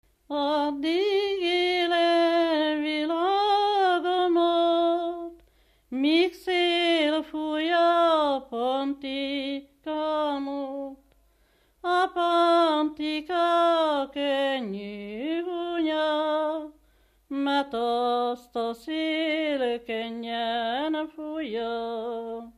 Moldva és Bukovina - Bukovina - Andrásfalva
Kitelepülés helye: Izmény
Stílus: 4. Sirató stílusú dallamok
Szótagszám: 8.8.8.8
Kadencia: 5 (b3) 4 1